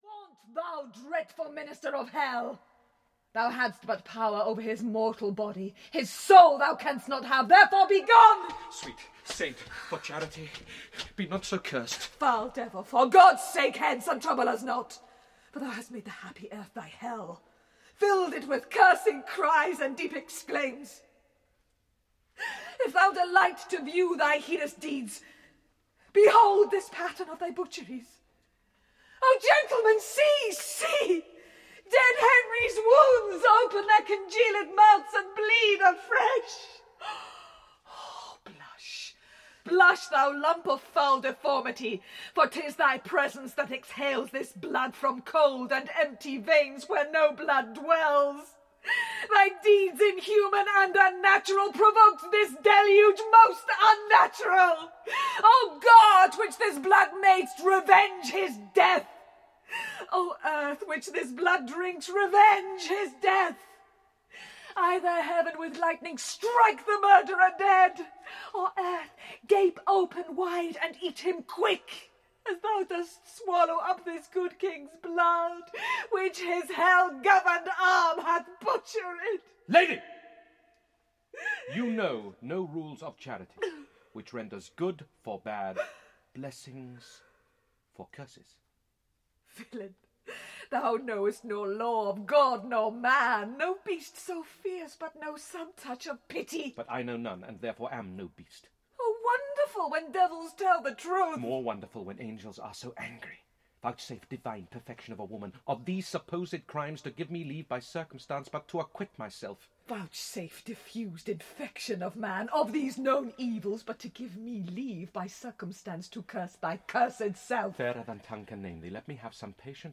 Kenneth Branagh heads an outstanding cast in playing one of Shakespeare’s strongest characters.
Ukázka z knihy